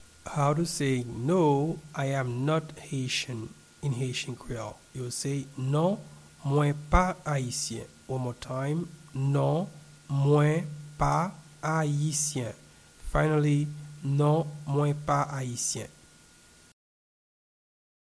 Pronunciation and Transcript:
No-I-am-not-Haitian-in-Haitian-Creole-Non-mwen-pa-ayisyen-pronunciation.mp3